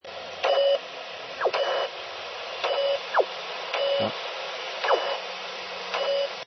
Ils ont été effectués à l'aide d'un camescope placé près du haut-parleur d'un MVT-7100.
Enregistrement 2 : SSB. Signal parasite + signal de la microbalise. On remarque que ce dernier est piaulé et que sa période est plus longue.